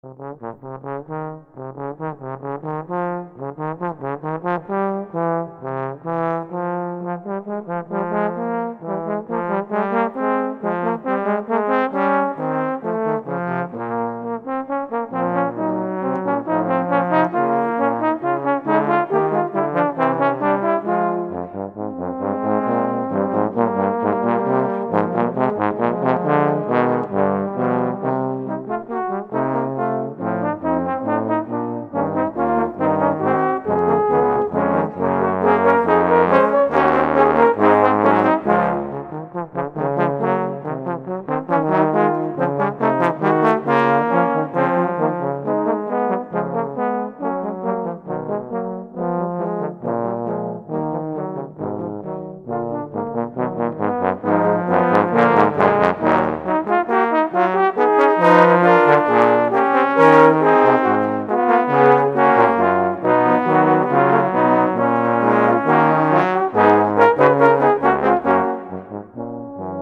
música académica costarricense